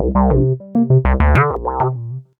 UR 303 (min loop) 2.wav